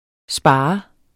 Udtale [ ˈsbɑːɑ ]